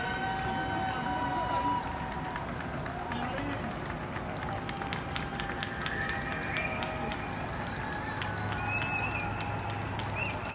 "The Ghost of Covid-19" Each evening at 7pm people appear on their balconies to clap, cheer and make noise for the Covid-19 first responders.
Covid-19 7pm cheer background noise) to remind us of our collective 7pm cheer for Covid-19 first responders…because, after all, there’s always 7pm somewhere in the world, a moment in time with a redefined symbolism.
covid19_1stresponders_jc_7pm_trimmed_audioonly.mp3.m4a